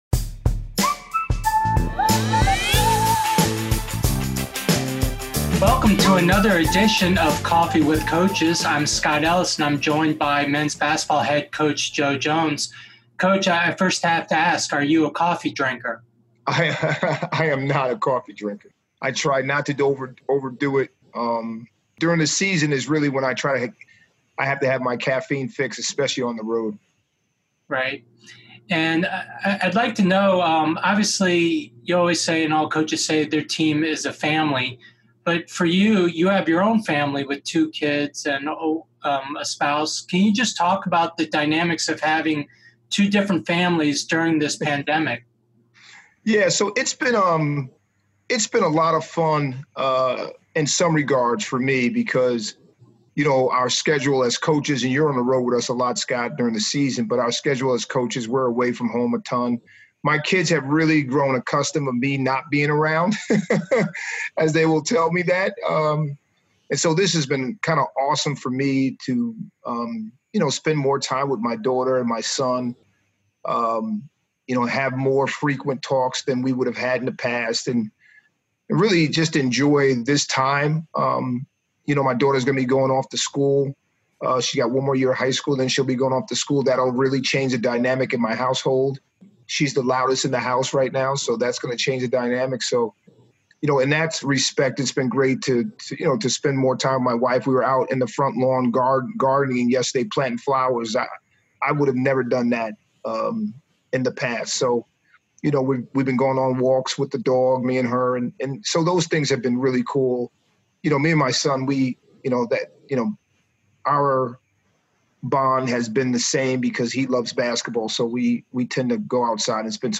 Each week we'll feature an interview with one of our coaches.